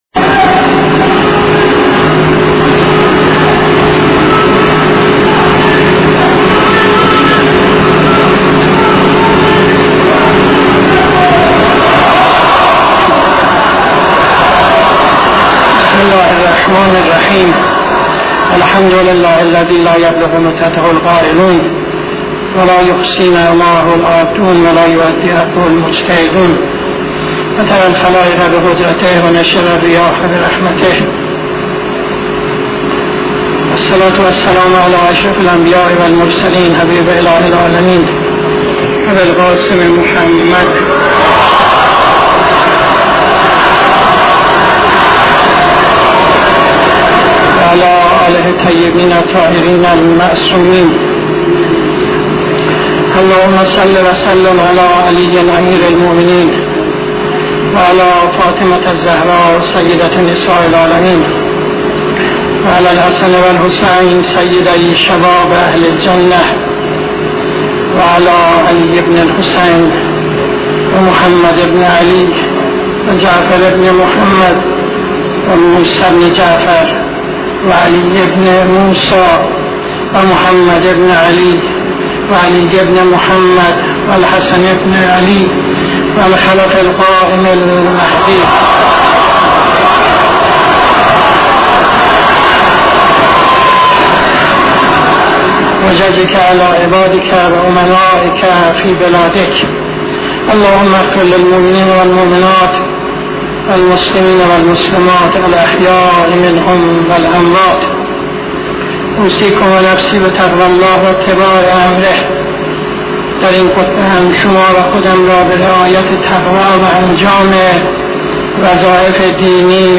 خطبه دوم نماز جمعه 01-02-74